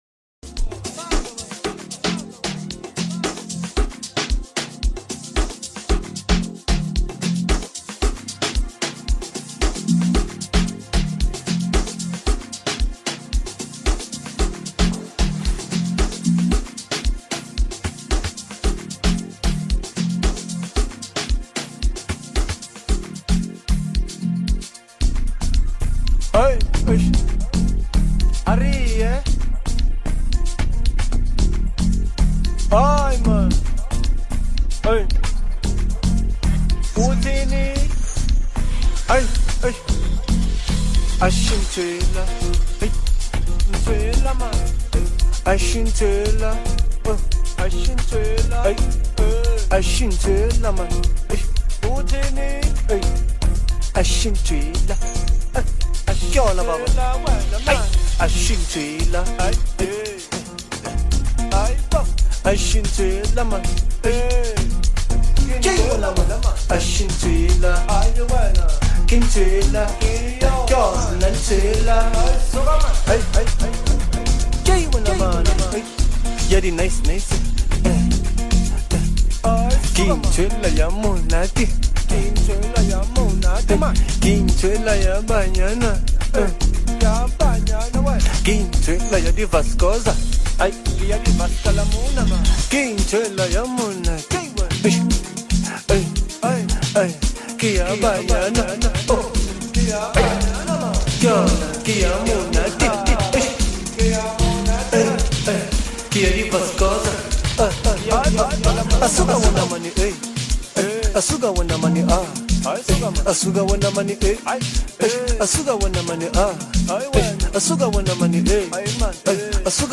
Home » Amapiano » DJ Mix » Hip Hop
South African singer